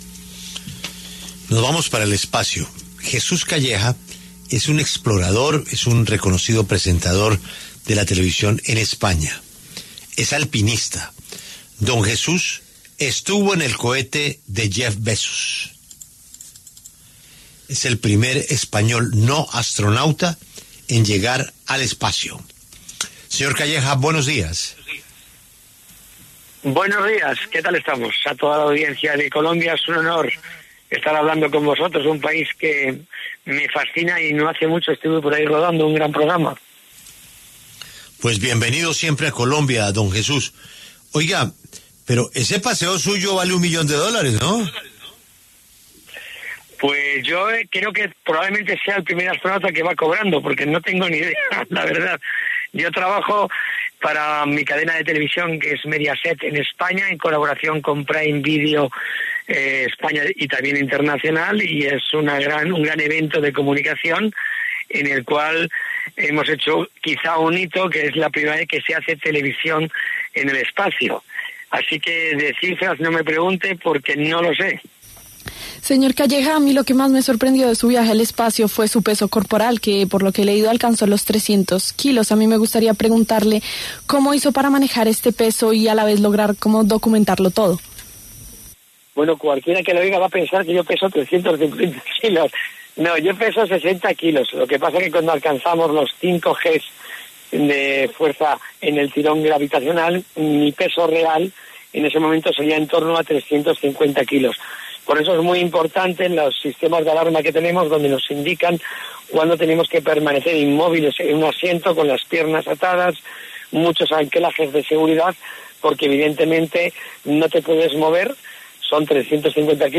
Jesús Calleja, presentador de televisión que viajó al espacio, pasó por los micrófonos de La W para relatar su experiencia.